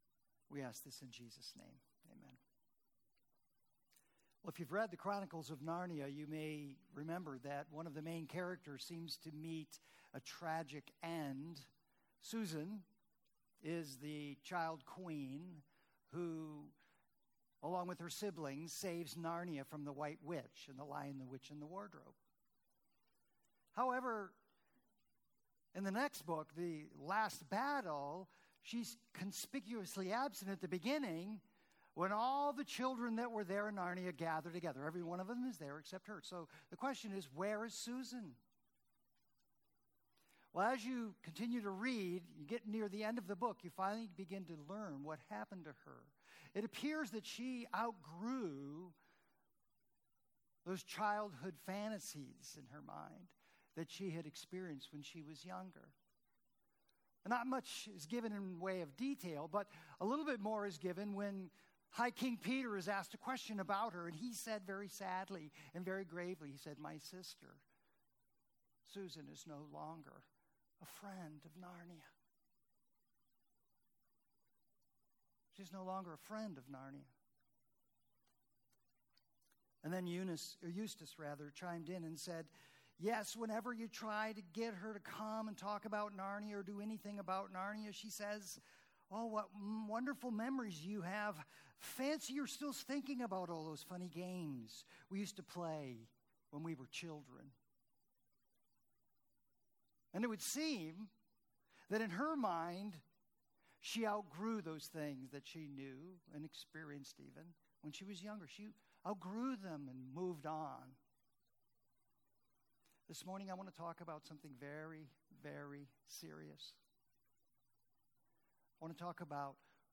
Home New Here Events About Our Beliefs Next Steps Meet our Team Ministries Bless App Kids Youth Women Men Senior Adults Prayer Give Contact Previous Sermons FAITH WORKS – Bring Him Back!